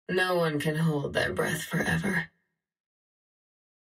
viper-character-selection-valorant-gaming-sound-effects-hd.mp3